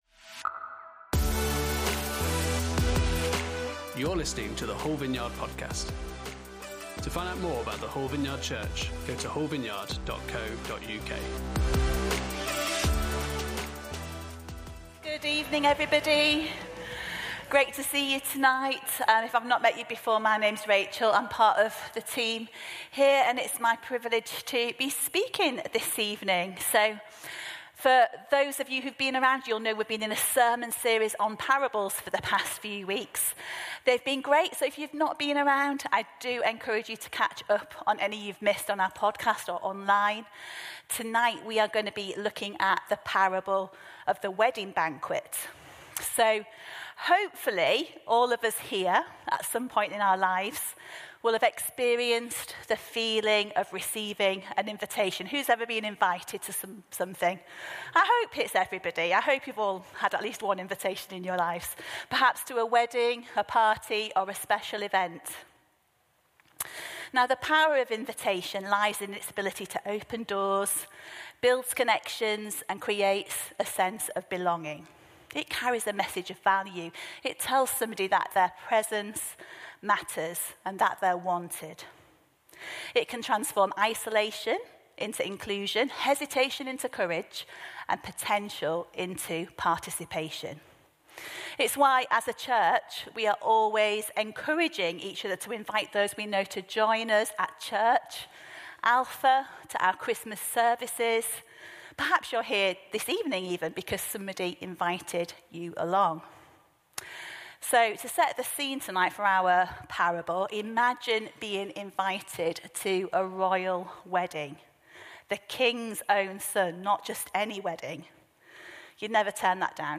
Parable Service Type: Sunday Service On Sunday evening